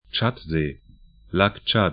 Tschadsee 'tʃat-ze: